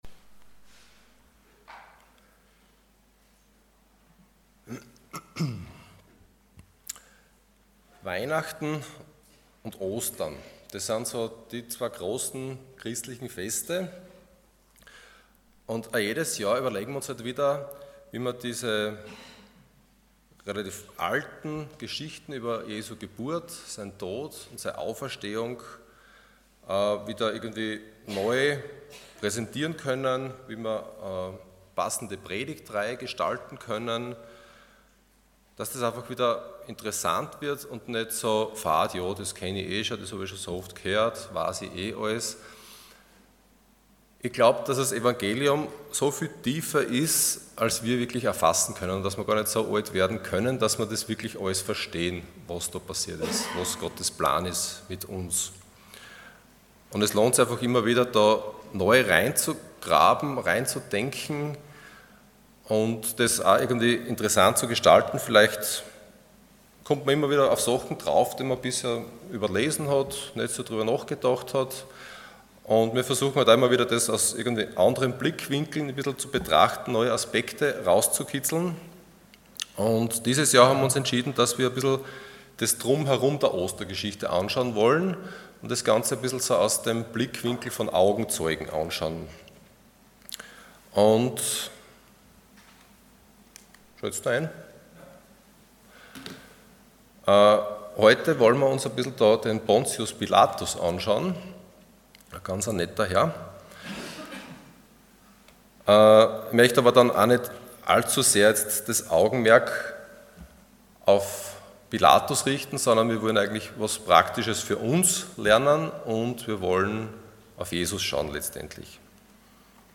Ostern aus der Sicht von Augenzeugen Dienstart: Sonntag Morgen